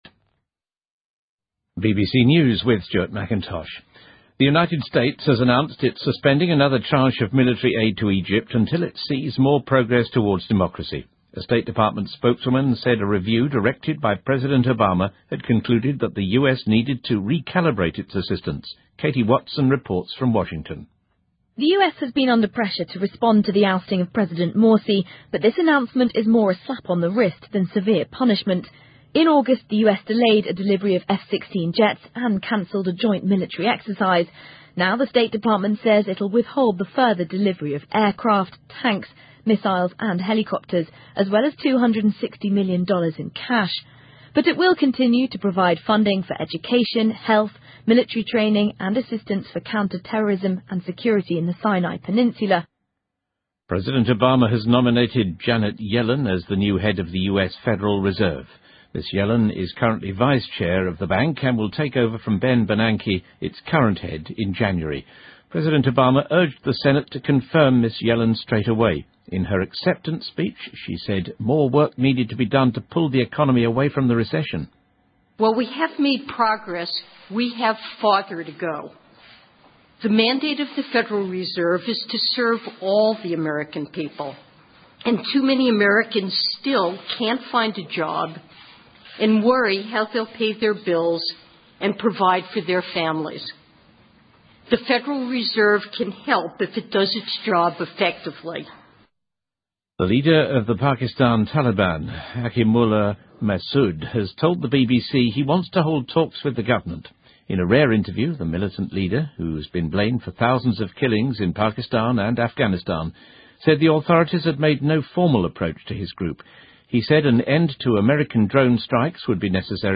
BBC news,奥巴马总统提名珍妮特·耶伦为美联储新任主席